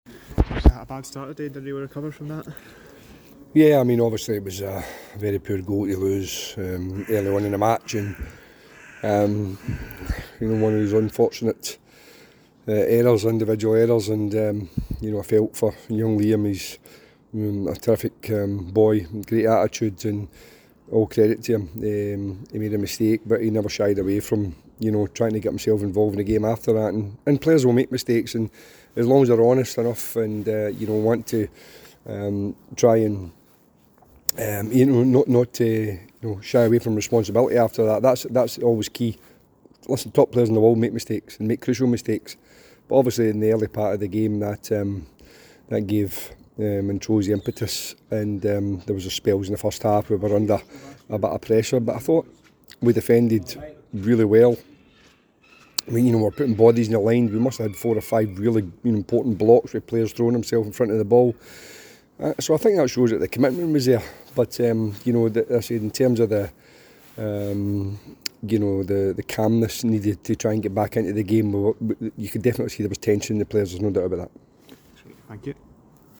post-match comments following the cinch League 1 fixture